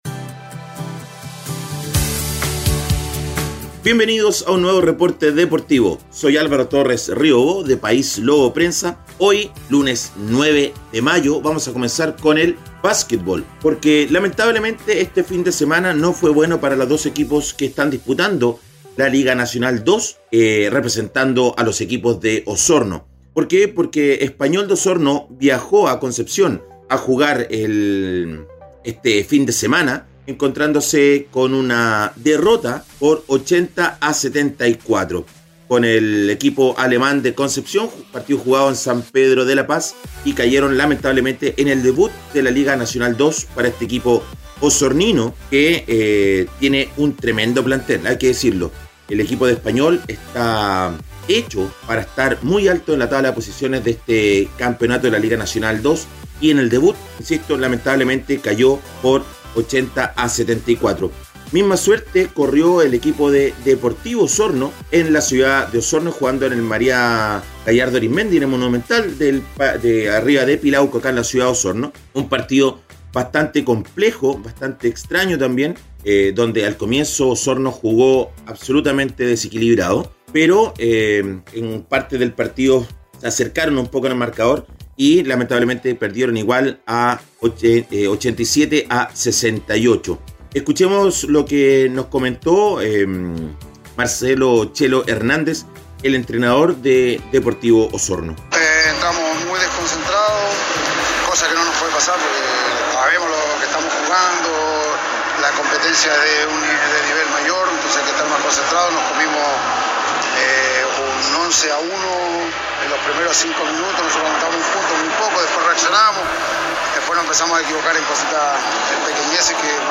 Reporte Deportivo ▶ Podcast 09 de mayo de 2022